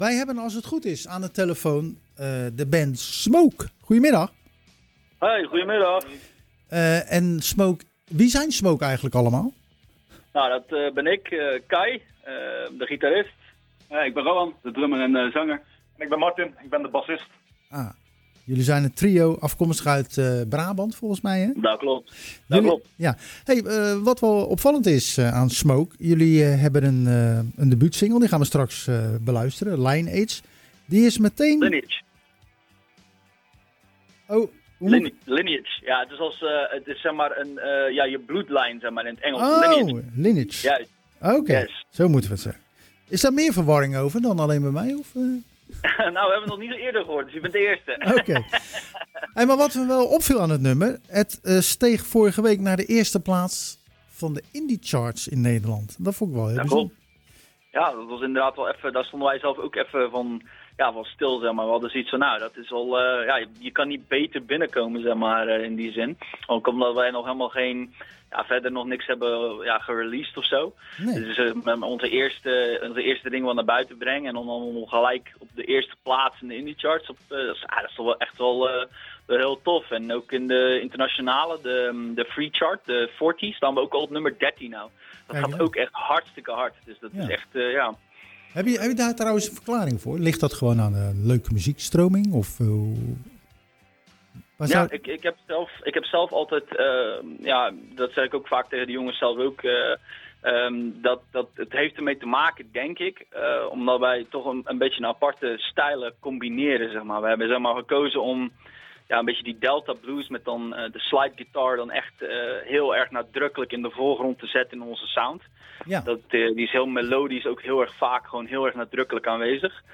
In het programma Zwaardvis kwam de bijzondere Brabantse band Smoke aan bod. We spraken de drie leden over de naam van de band, het succes van debuutsingle Lineage en de videoclip behorend bij het nummer.�Smoke speelt psychedelische swamp-rock.